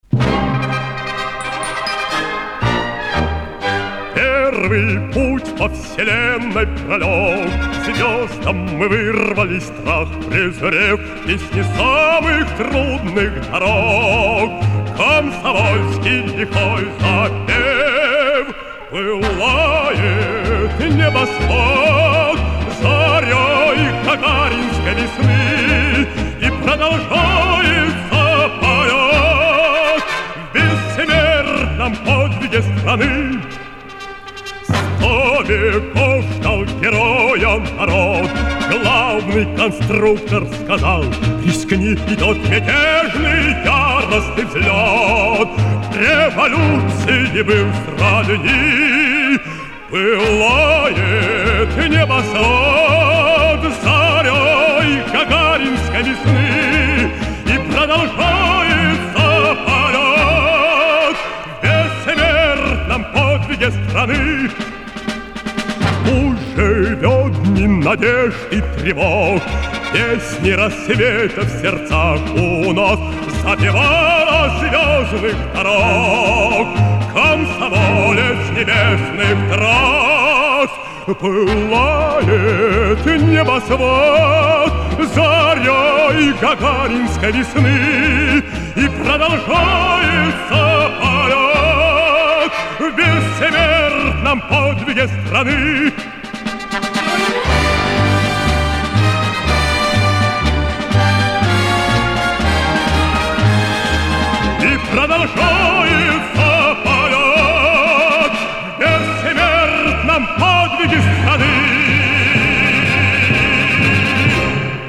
Концертный вариант 1973 года: